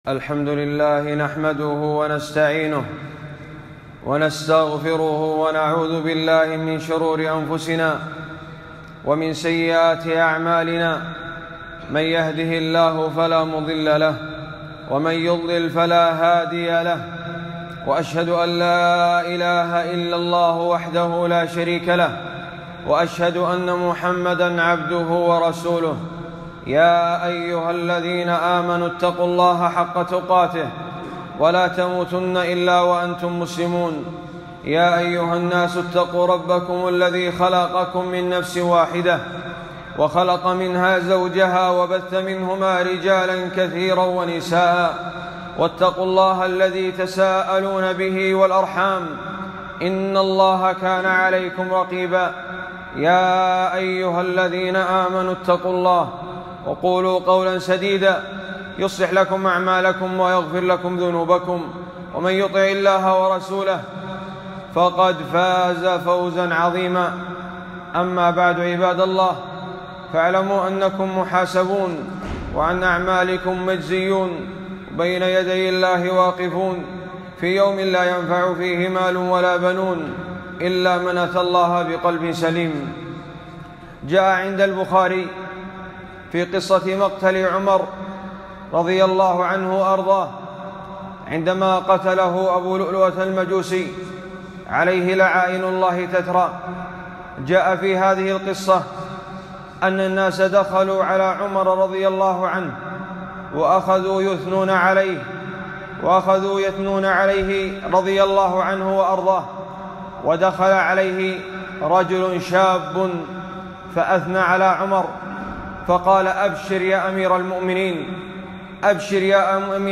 خطبة - قصة عمر رضي الله عنه وهو في حالة موته مع الشاب